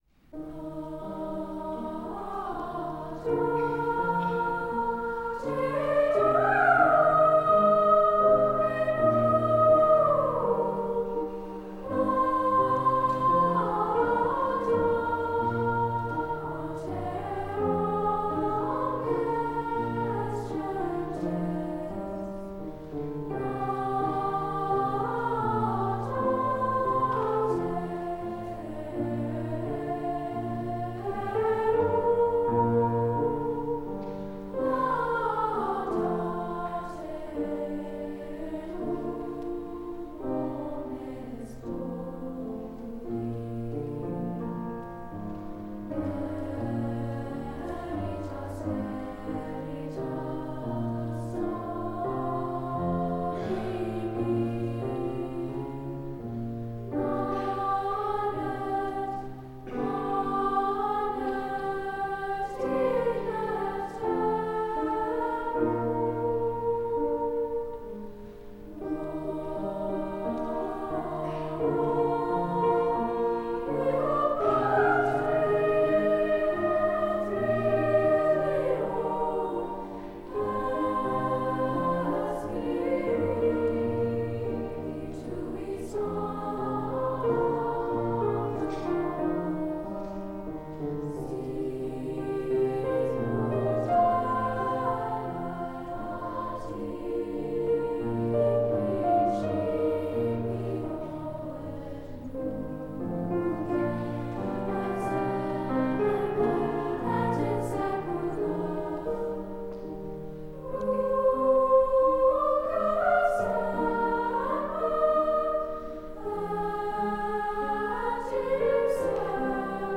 SSA